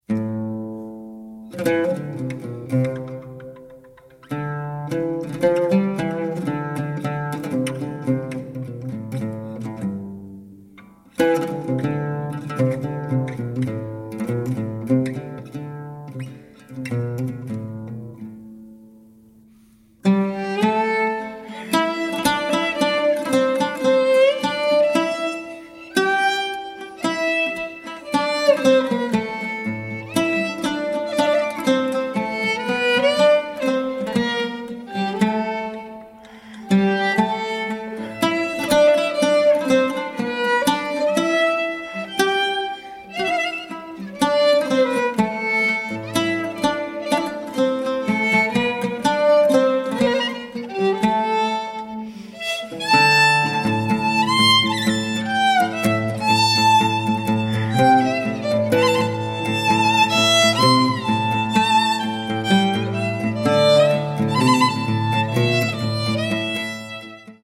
extended piano, oud, violin, cello and percussion
experimental chamber ensemble
Recorded October 1, 2024 at Miller Theatre, NYC